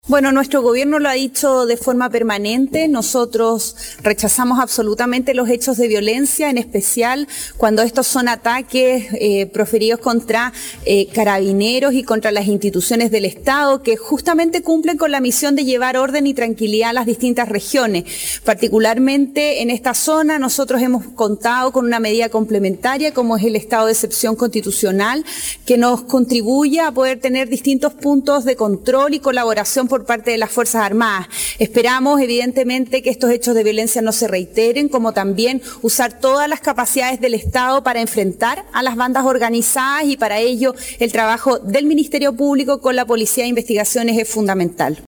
Durante el punto de prensa, Siches se refirió al ataque que recibió la Tenencia de Capitán Pastene durante el fin de semana y a otros hechos de violencia registrados en la macrozona sur.